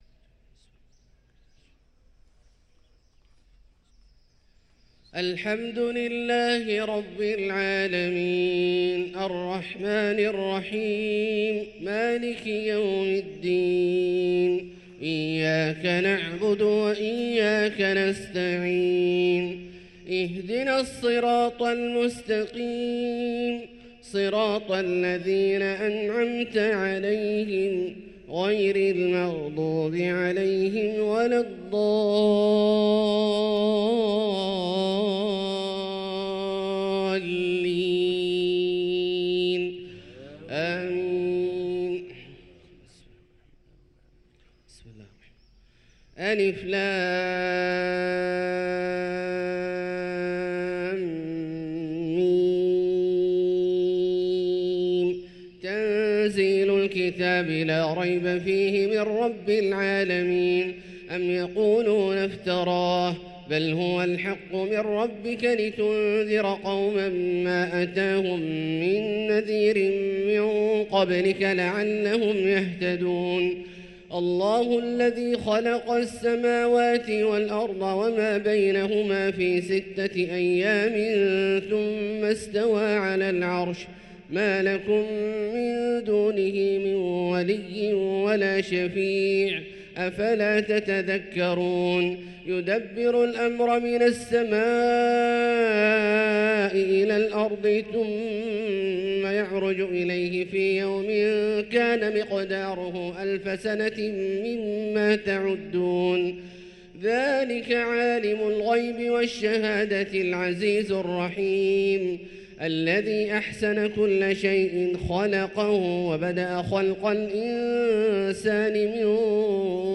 صلاة الفجر للقارئ عبدالله الجهني 23 جمادي الآخر 1445 هـ
تِلَاوَات الْحَرَمَيْن .